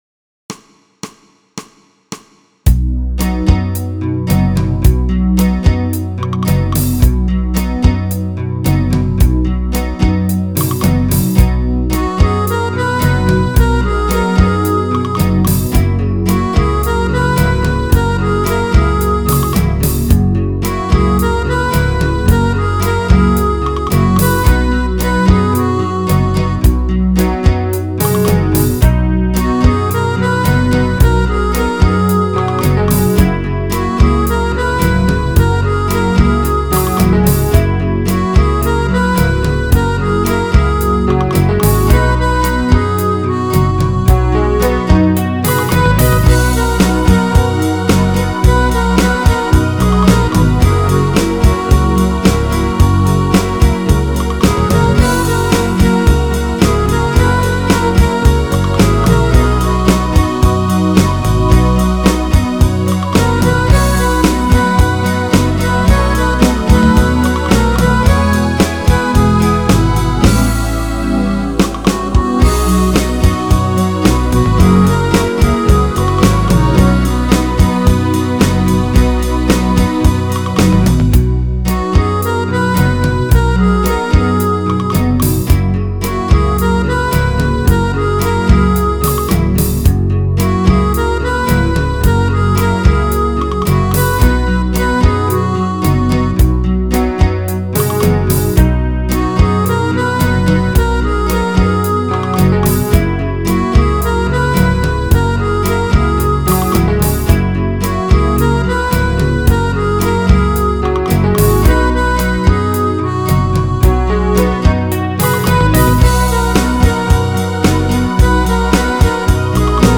It’s easy to dance to and very recognizable.